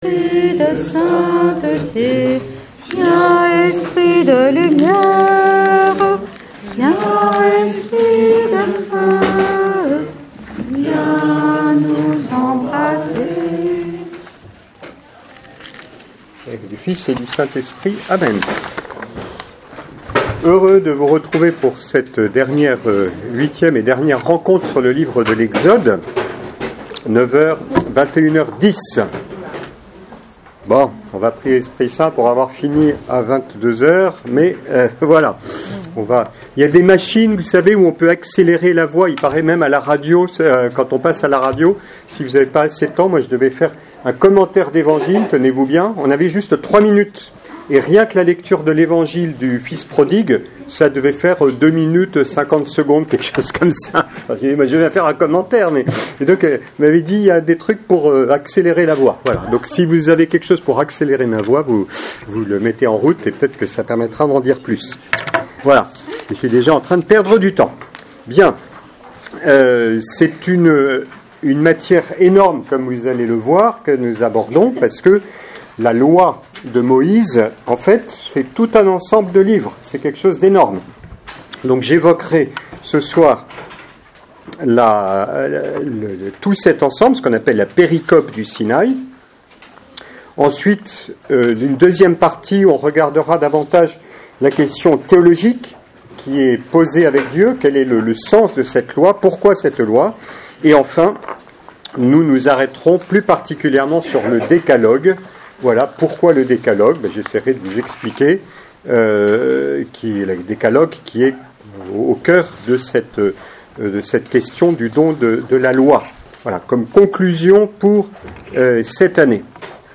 4ème cours